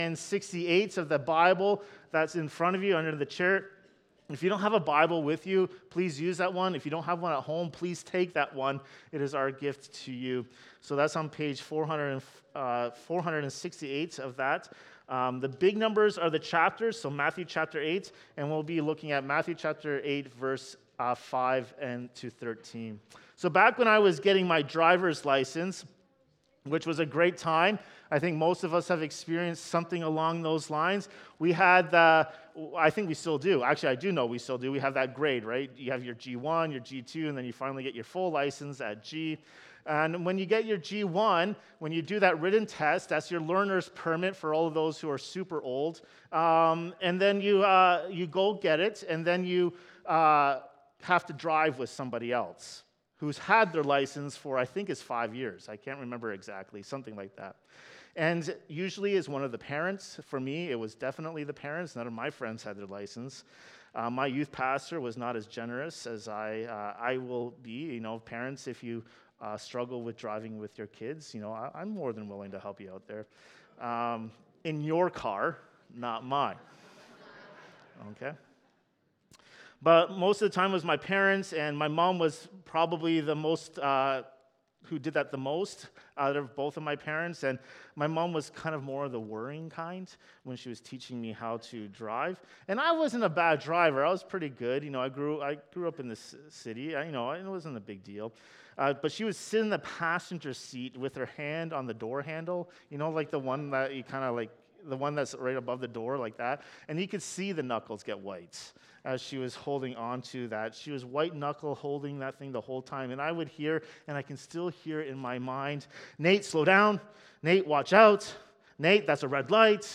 This sermon explores biblical faith, warns against presumption, and calls us to rest under Christ’s finished work.